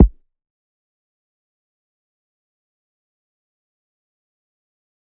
MB Kick (8).wav